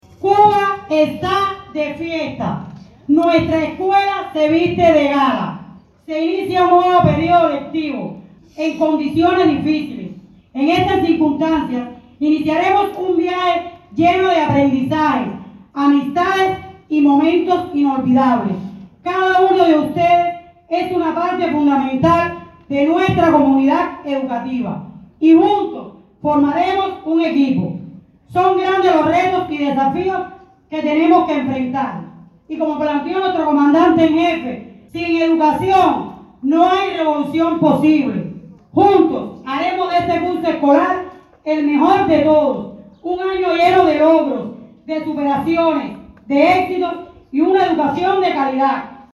El acto municipal, acaecido en la escuela politécnica Leonor Pérez Cabrera, del poblado, estuvo presidido por las máximas autoridades políticas y gubernamentales, así como representantes provinciales y territoriales del sector educacional.
La cita devino escenario para ponderar aquellas metas y proyectos a materializar en pro de un período lectivo orientado a perfeccionar el proceso docente-educativo. Así lo refirió en las palabras de bienvenida